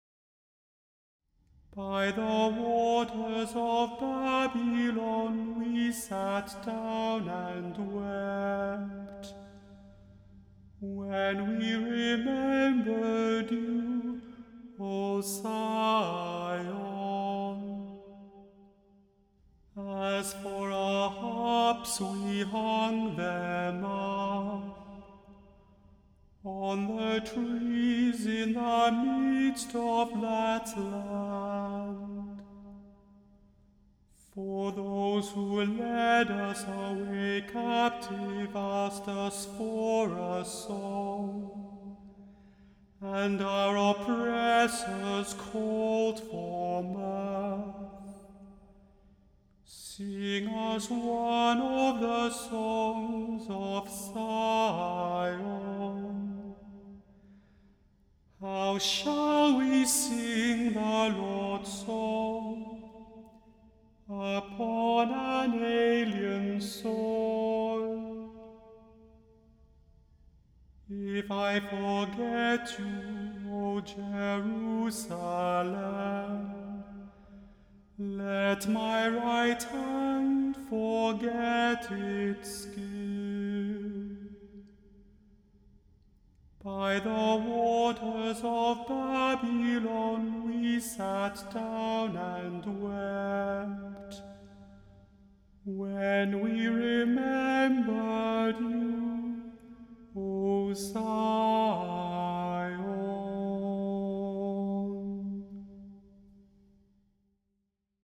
The Chant Project – Chant for Today (May 28) – Psalm 137 vs 1-5 – Immanuel Lutheran Church, New York City
The Chant Project – Chant for Today (May 28) – Psalm 137 vs 1-5